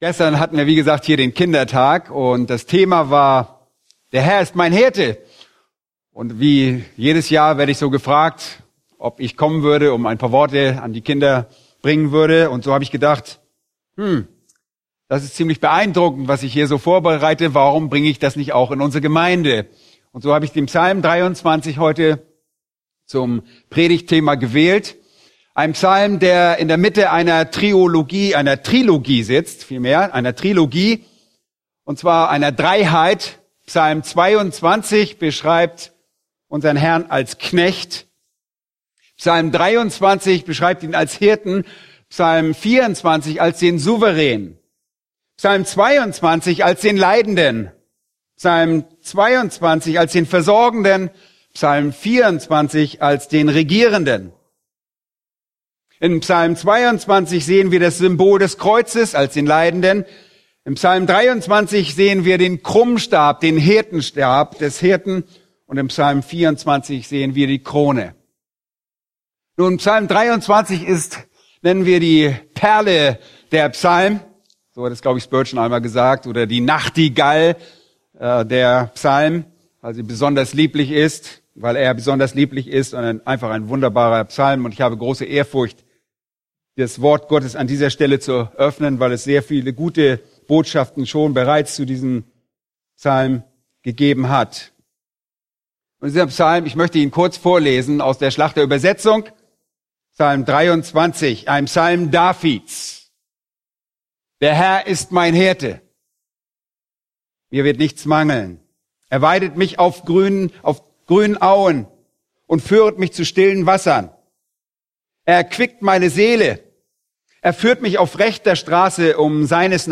Eine predigt aus der serie "Weitere Predigten." Psalm 23